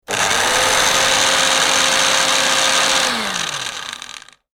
料理用ミキサー1.mp3